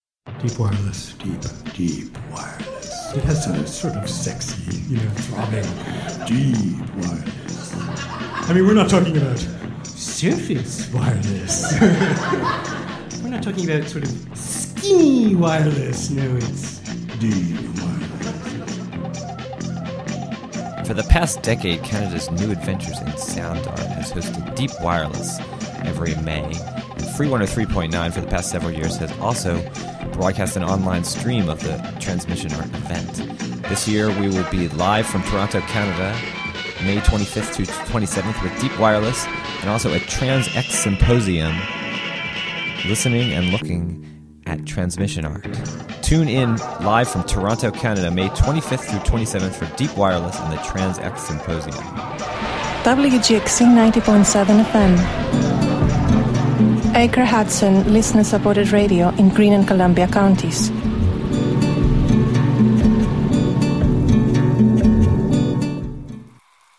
Wave Farm | An Official WGXC PSA for WGXC's live coverage from Toronto of Deep Wireless festival and Trans X Symposium.